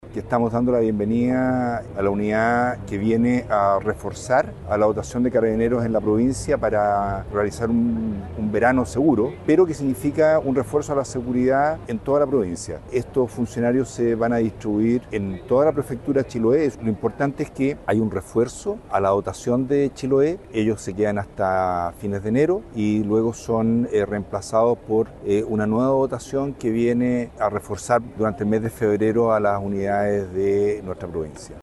El delegado presidencial provincial de Chiloé, Marcelo Malagueño, explicó que este refuerzo busca responder al aumento de visitantes durante el verano y fortalecer la presencia policial en sectores de alta concurrencia.
delegado-llegada-carabineros.mp3